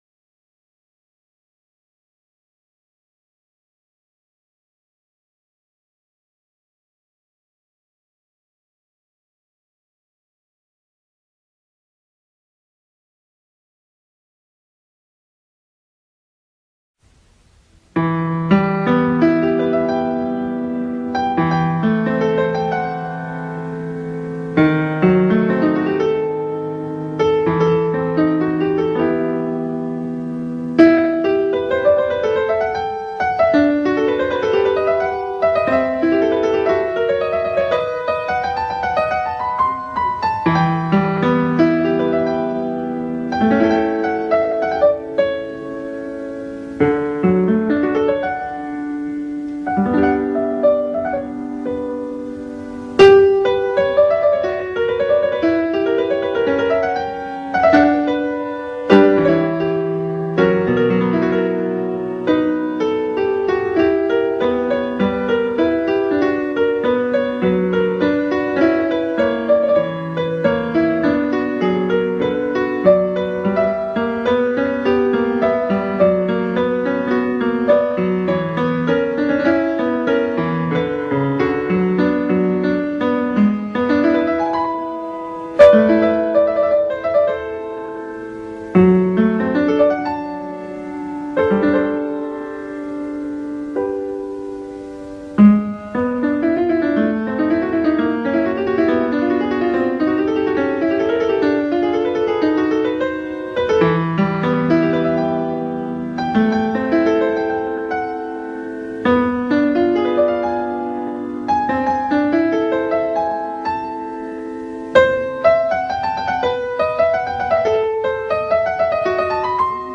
آموریم و همسرش اینگونه منچستر را ترک کردند / فیلم برچسب‌ها: Music GlenGould Piano Bach Glenn Gould یوهان سباستین باخ دیدگاه‌ها (3 دیدگاه) برای ارسال دیدگاه وارد شوید.